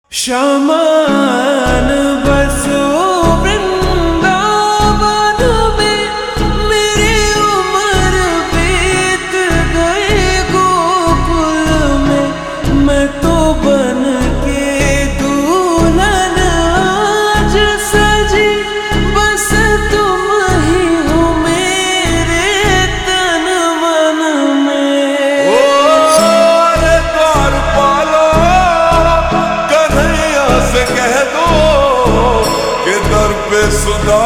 Slow Reverb Version
• Simple and Lofi sound